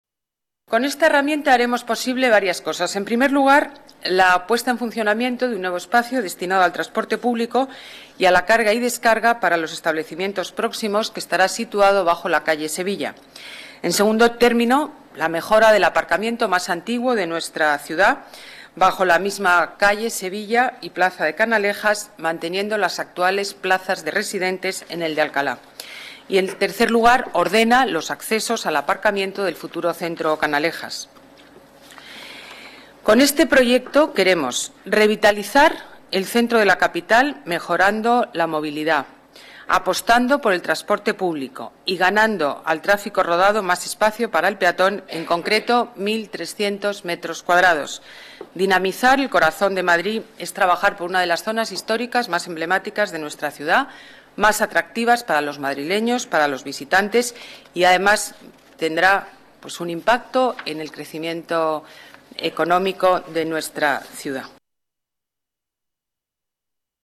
Nueva ventana:Declaraciones de la alcaldesa de Madrid, Ana Botella